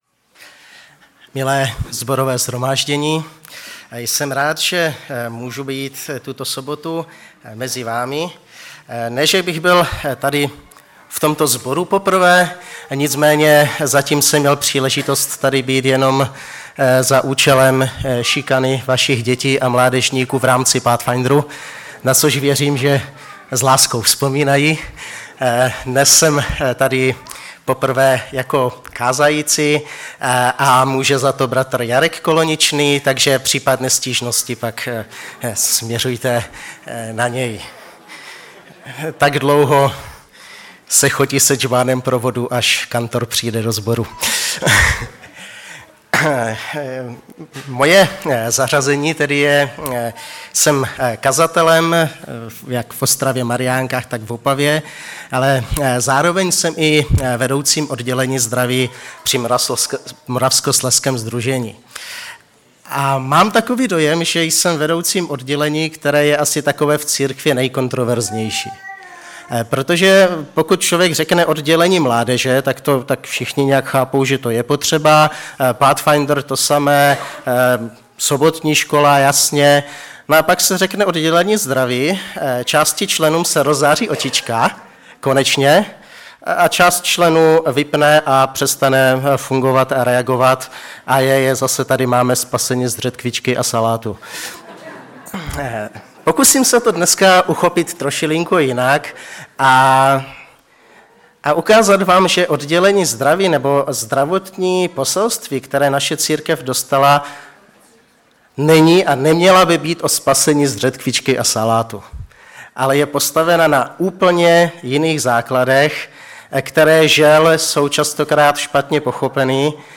Kazatel